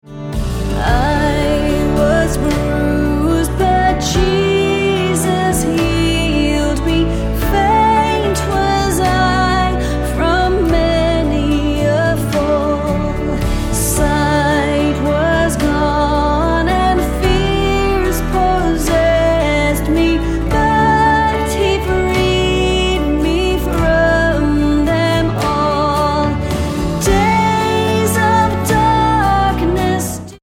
D